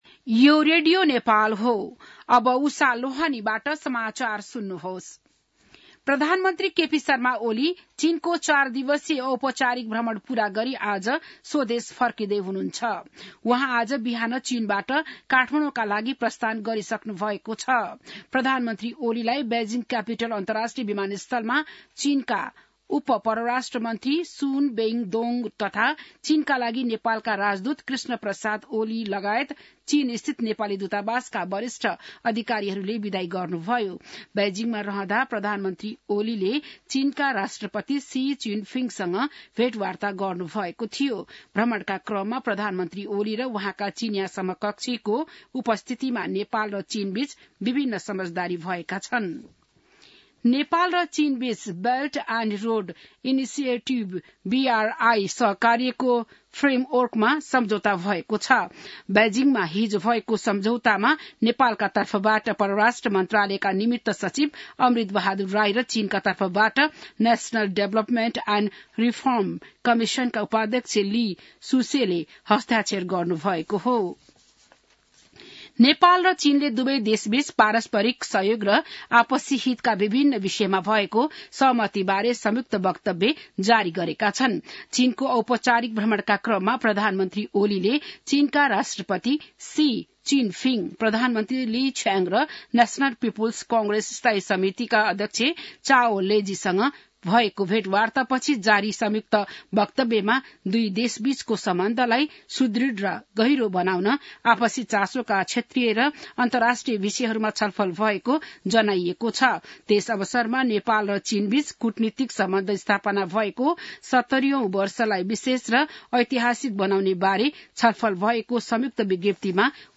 बिहान १० बजेको नेपाली समाचार : २१ मंसिर , २०८१